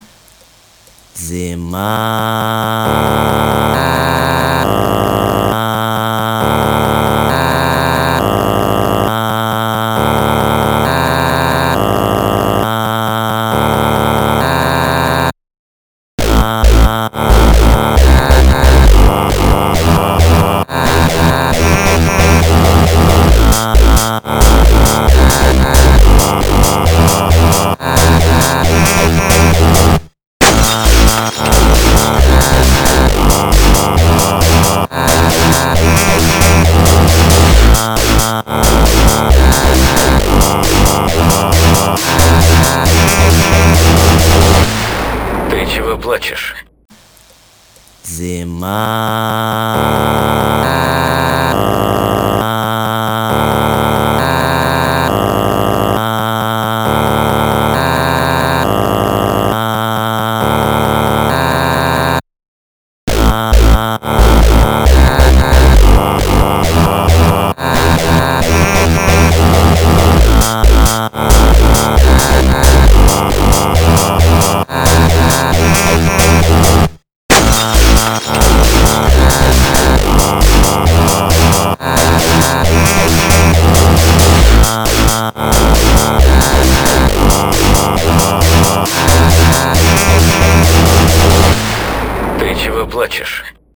Slowed Tik Tok version